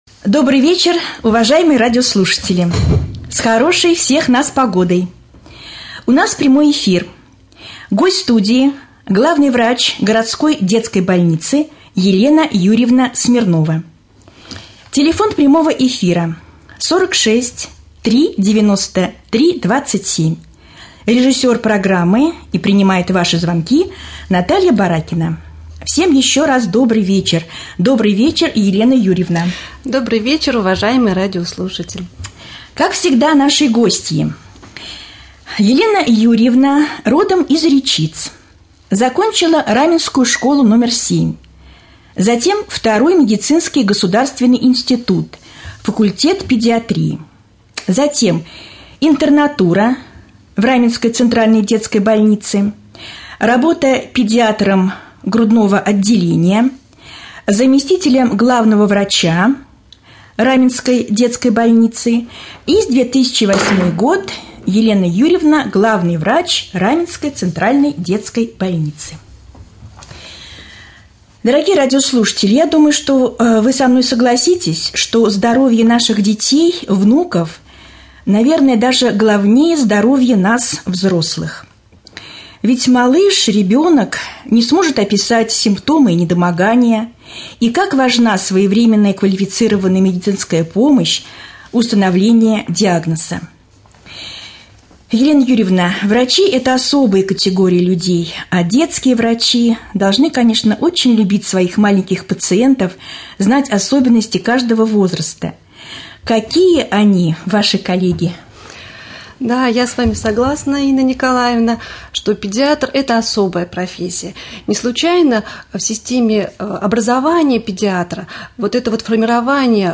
2.Прямой-эфир1.mp3